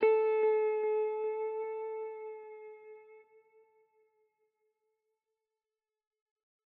Electric Guitar - Jazz Thin.wav